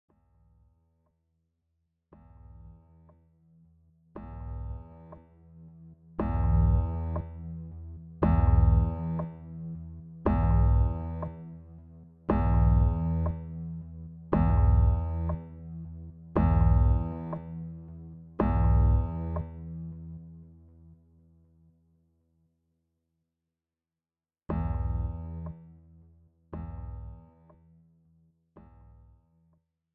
Without Backing Vocals. Professional Karaoke Backing Tracks.
This is an instrumental backing track cover.
Key – E